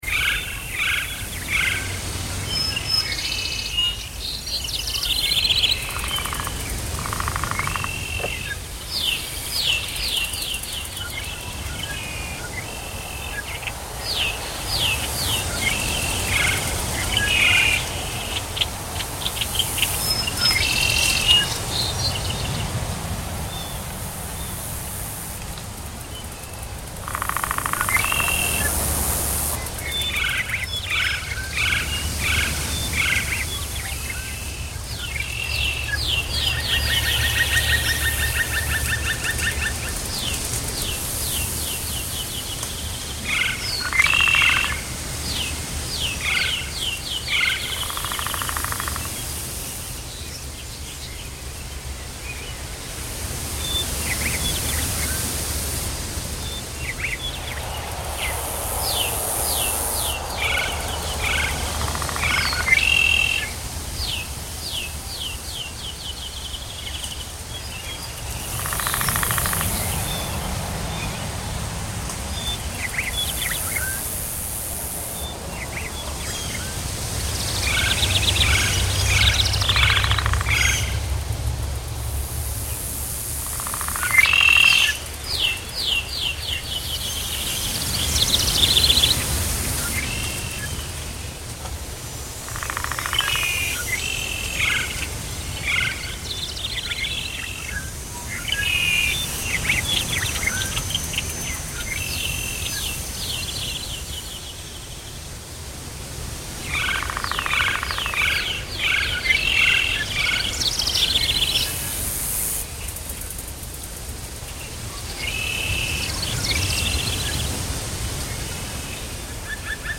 forest-day-2.ogg